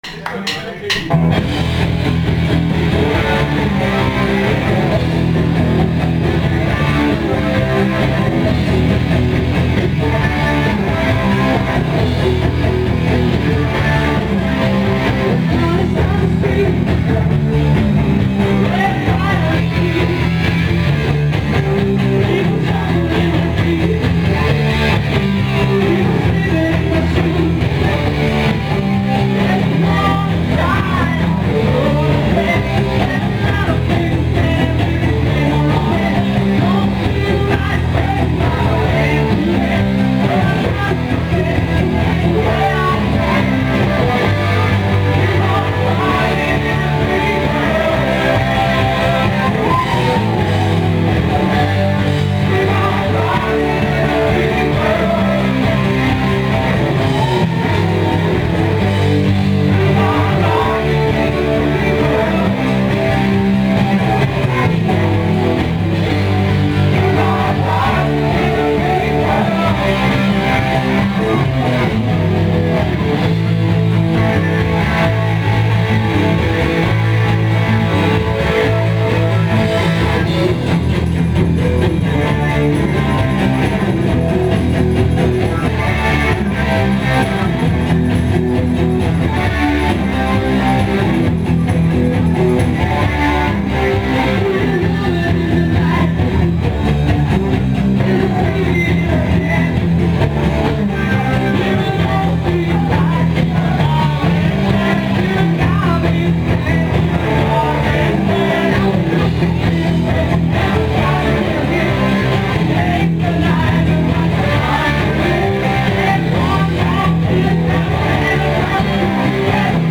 Sang
Guitarer
Live Bjæverskov